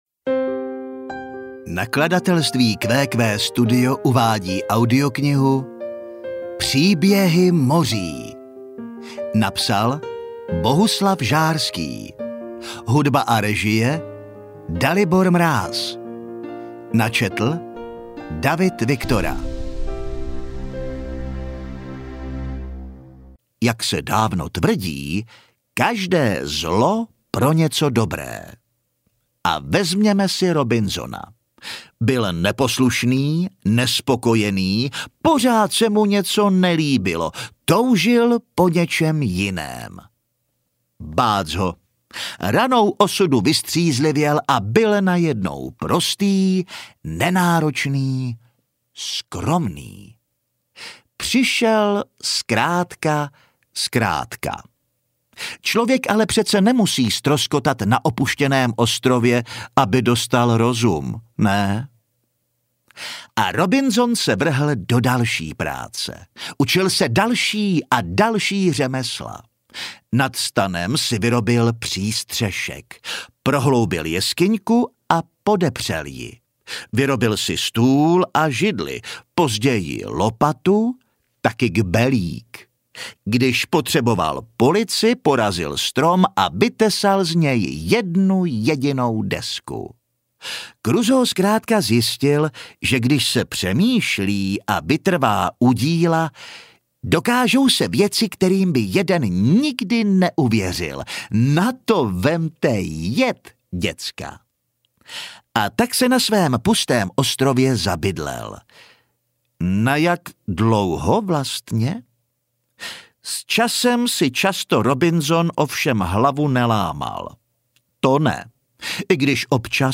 Příběhy moří audiokniha
Ukázka z knihy